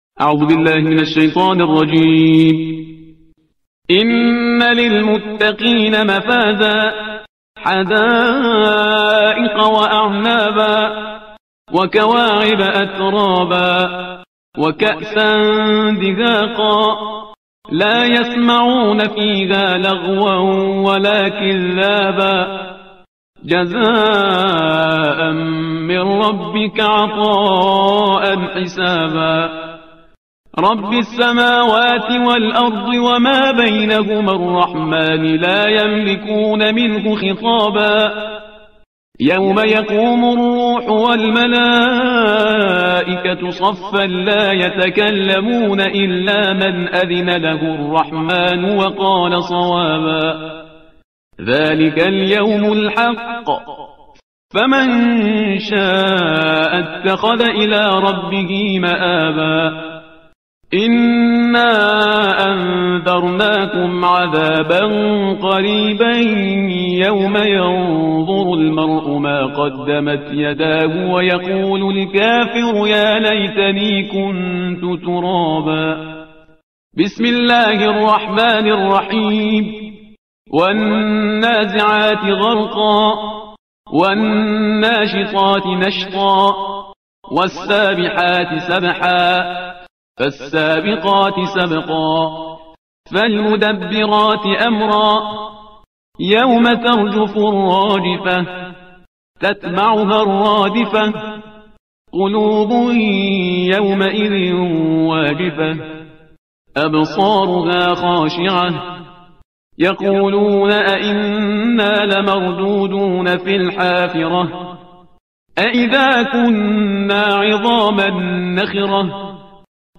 ترتیل صفحه 583 قرآن با صدای شهریار پرهیزگار